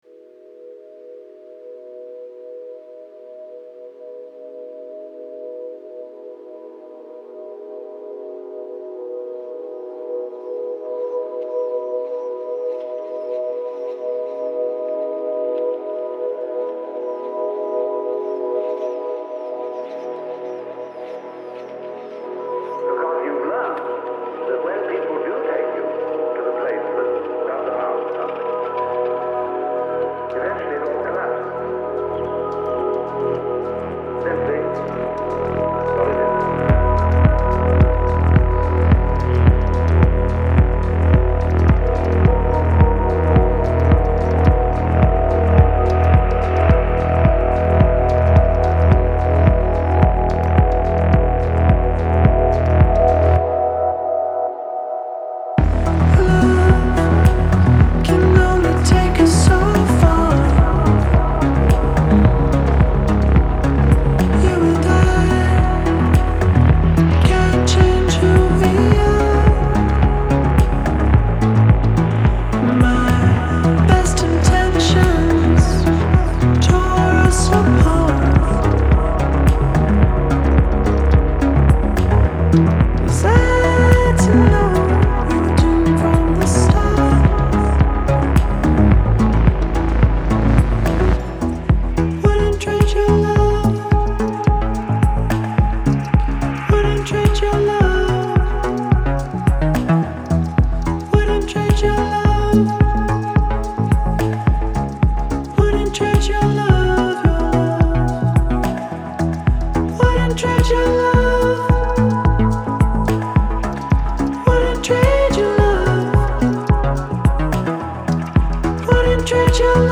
buoyant, wistful electronic pop